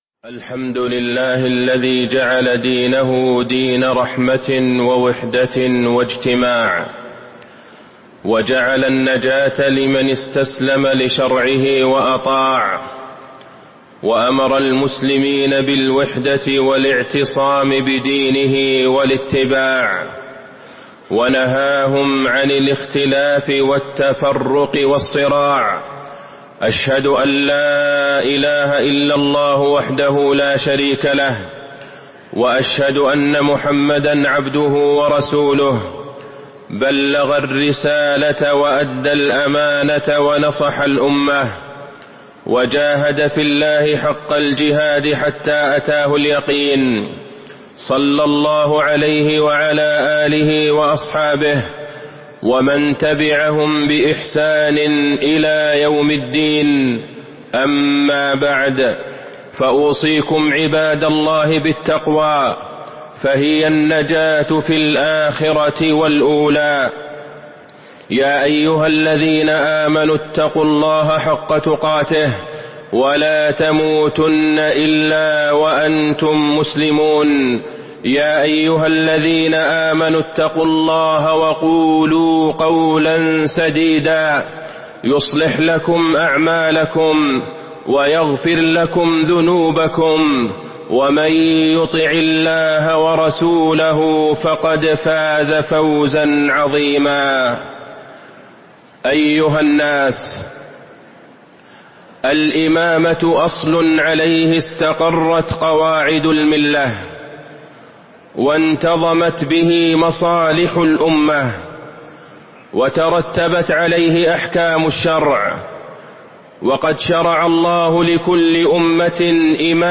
تاريخ النشر ١٣ محرم ١٤٤٦ هـ المكان: المسجد النبوي الشيخ: فضيلة الشيخ د. عبدالله بن عبدالرحمن البعيجان فضيلة الشيخ د. عبدالله بن عبدالرحمن البعيجان السمع والطاعة لولاة الأمر